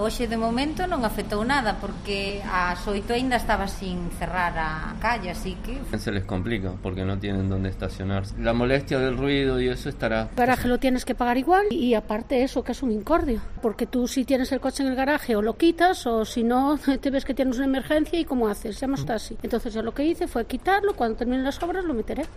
Vecinos y comerciantes sobre el corte de la calle Poza de Bar